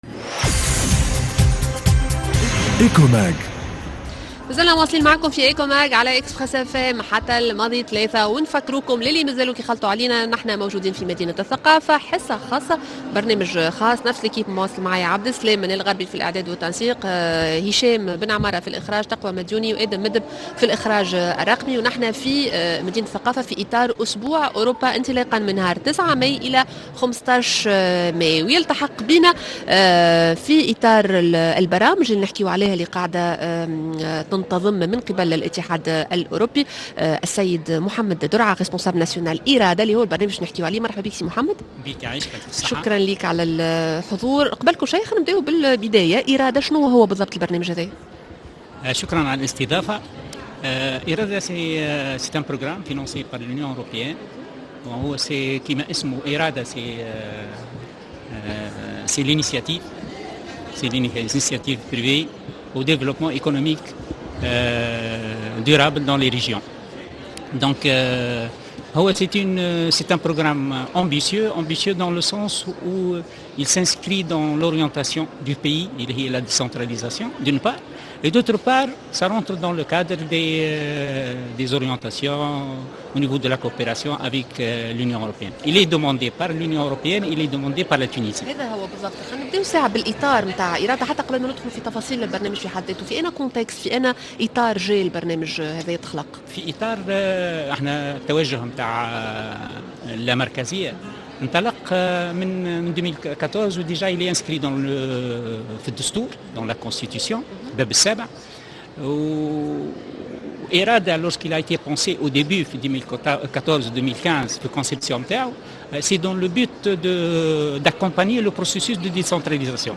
La grande interview